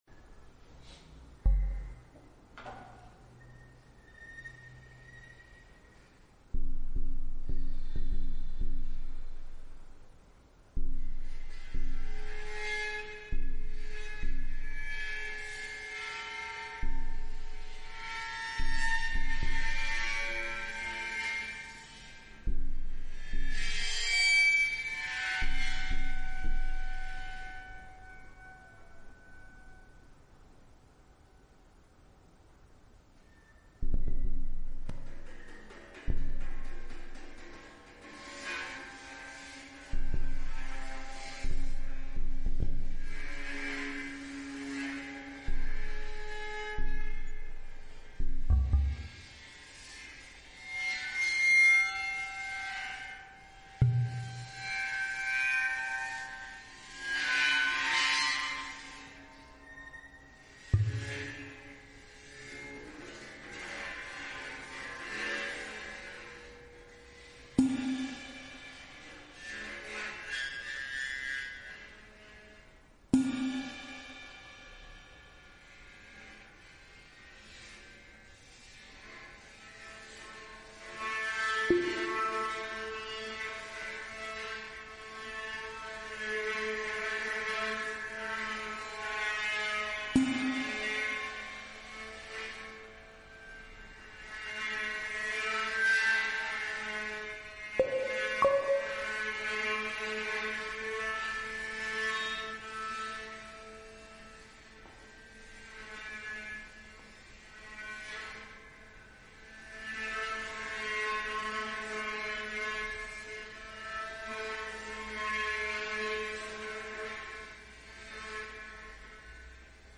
V petek, 18. novembra 2022 ob 20.00 vabljeni v Cirkulacijo 2, Podhod Ajdovščina Ljubljana, na plesno-glasbeno predstavo
Tolkala, bobni = percussion
Dislocirana tolkala = percussion